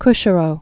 (kshə-rō, k-shērô)